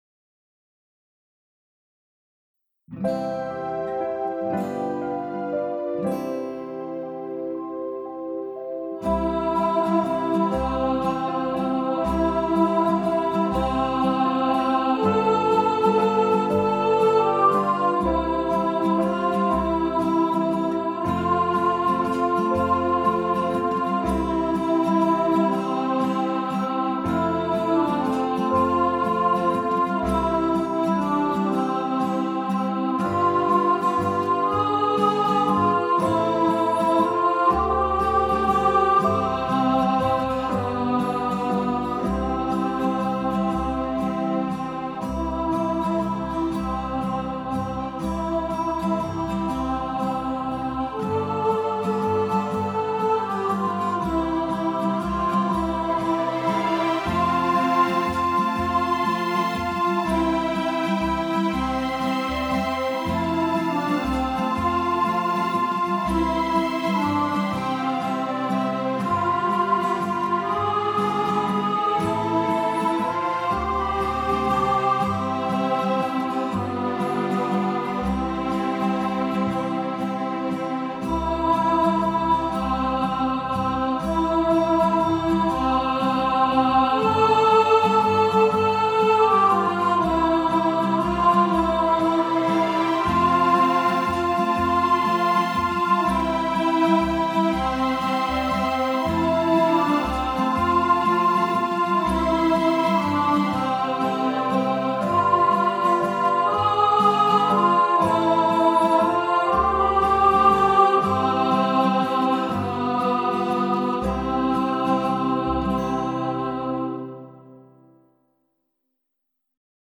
Silent-Night-Alto.mp3